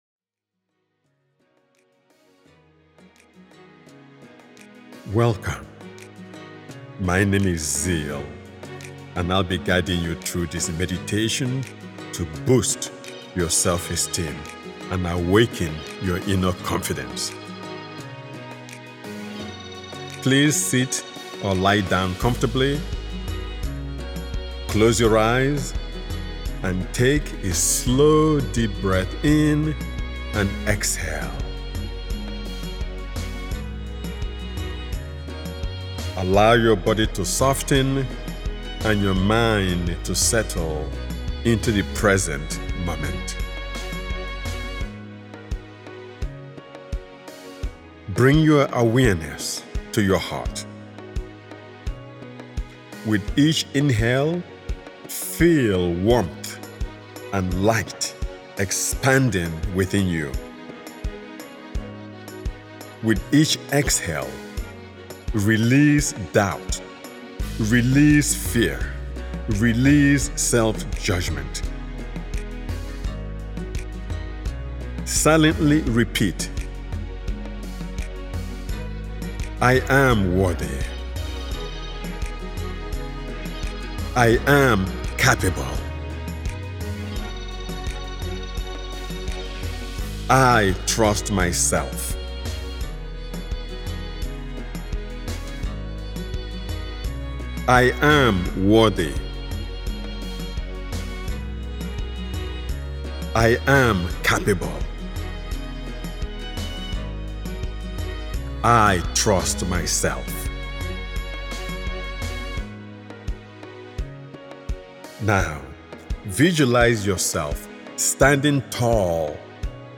Awakening Confidence: A Meditation for Self-Esteem, Inner Strength & Personal Empowerment